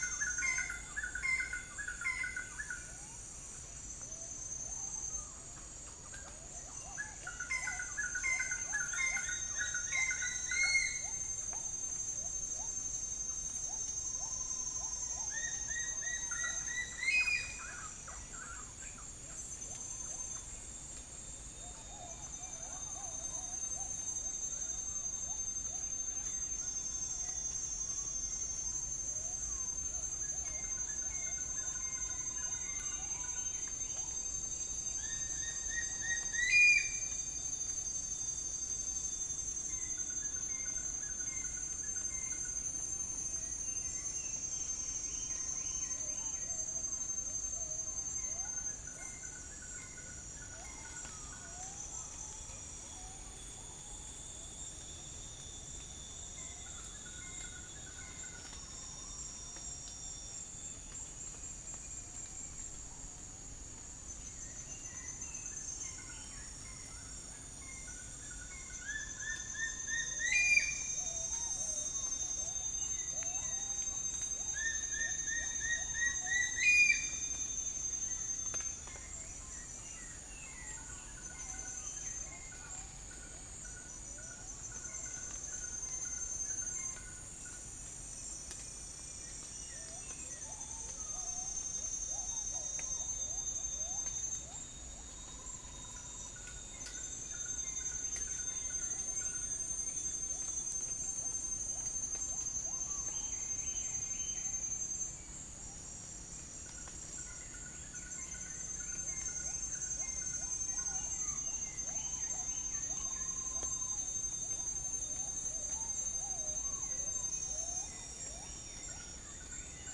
Cyanoderma rufifrons
Oriolus xanthonotus
Nisaetus cirrhatus